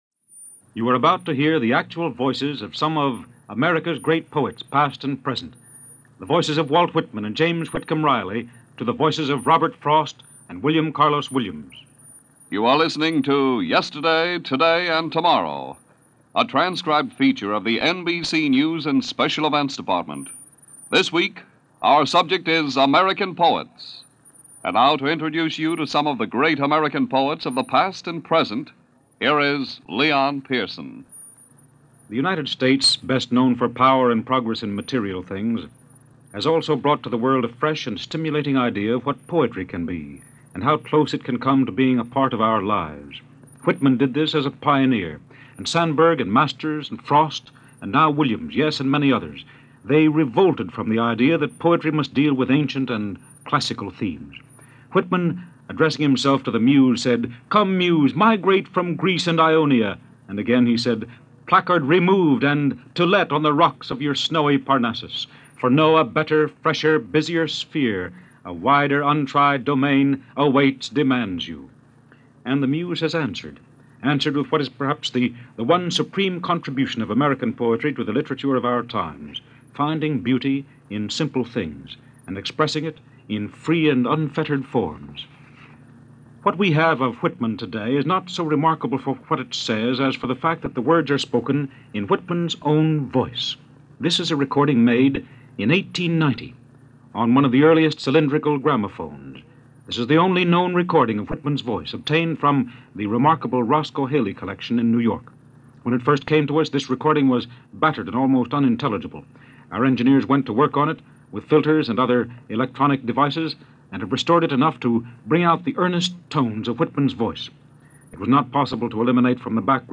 What’s up is the only known recording of Walt Whitman, taken from a cylinder made in 1890.
In addition to Whitman are the voices of James Whitcomb Riley from 1903, Edwin Markham , Robert Frost , Edna St. Vincent Millay , and an interview with William Carlos Williams in 1951. It was part of a radio series, Yesterday, Today and Tomorrow and broadcast on August 5, 1951.